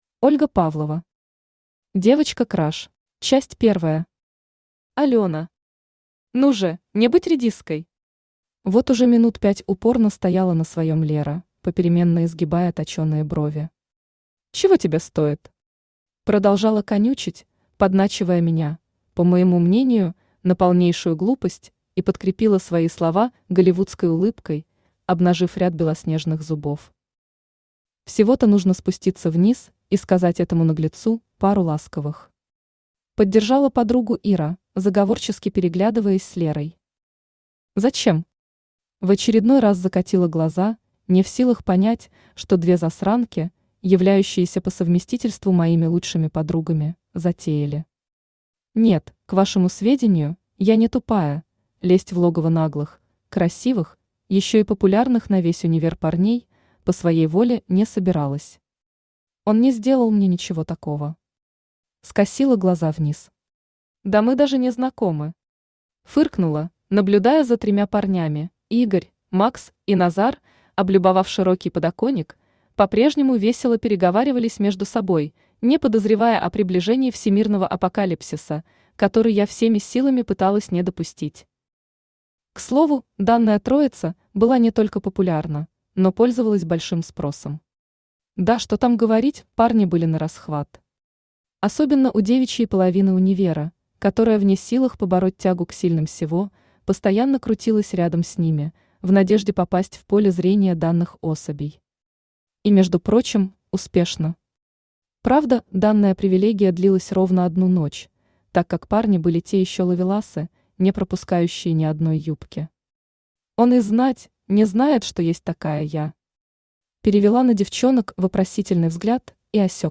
Aудиокнига Девочка краш Автор Ольга Анатольевна Павлова Читает аудиокнигу Авточтец ЛитРес.